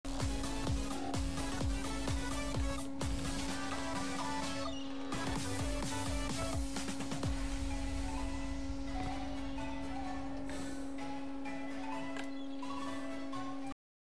here is what it looks like in TU right now (sorry about the slight ringing noise, it seems i left my mic on when recording)